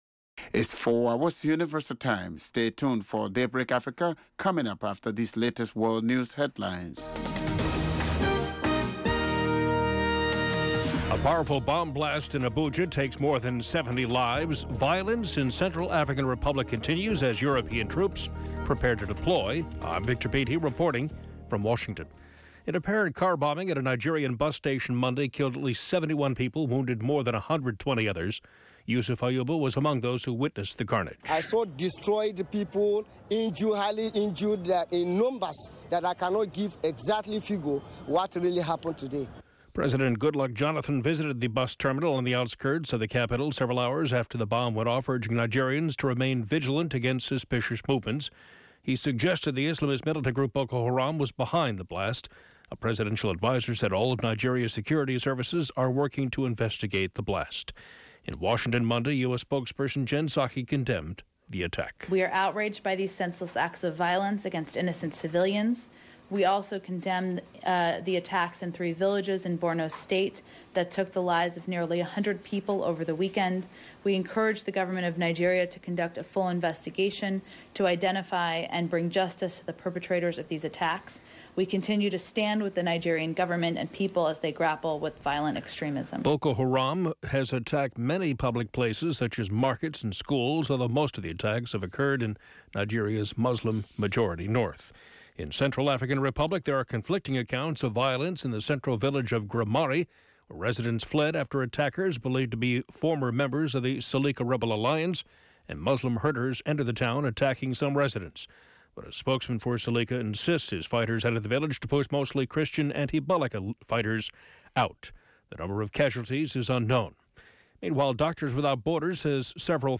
Each morning, Daybreak Africa looks at the latest developments on the continent, starting with headline news and providing in-depth interviews, reports from VOA correspondents, sports news as well as listener comments.